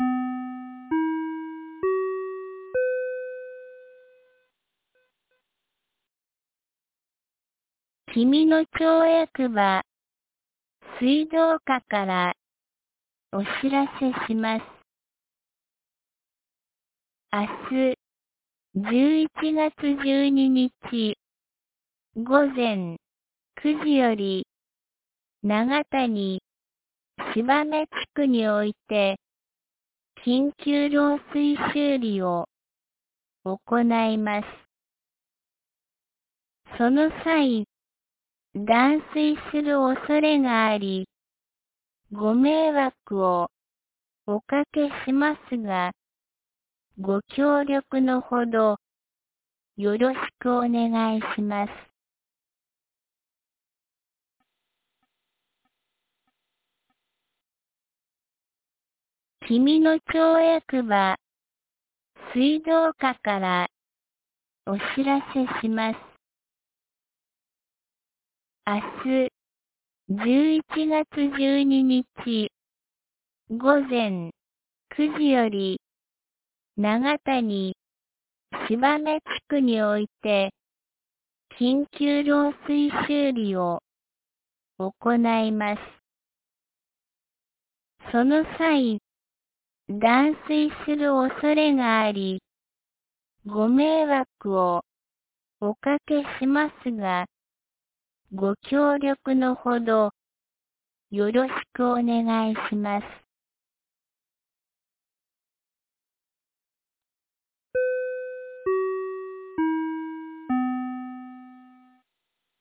2025年11月11日 15時01分に、紀美野町より東野上地区へ放送がありました。